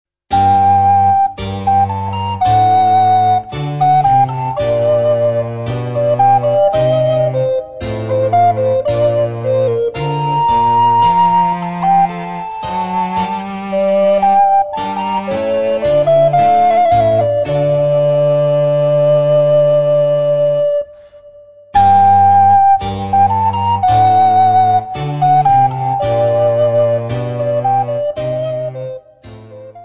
Besetzung: Altblockflöte und Basso continuo